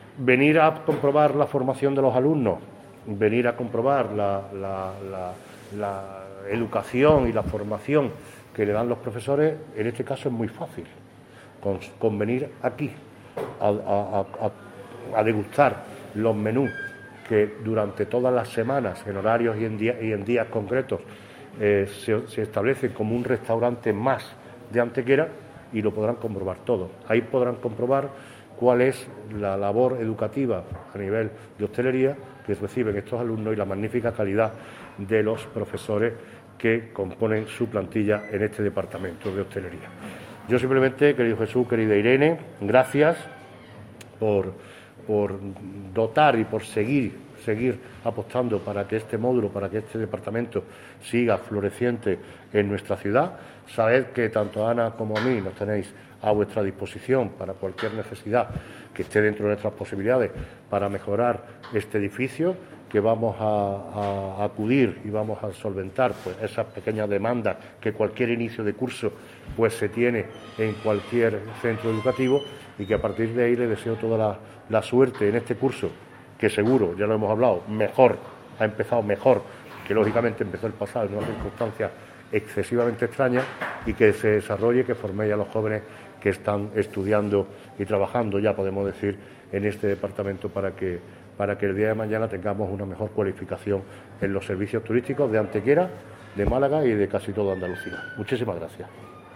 El alcalde Manolo Barón visita la Escuela de Hostelería de El Henchidero con motivo del inicio de un nuevo curso con 135 alumnos y 17 profesores para 4 titulaciones
Cortes de voz